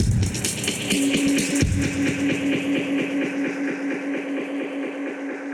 Index of /musicradar/dub-designer-samples/130bpm/Beats
DD_BeatFXB_130-01.wav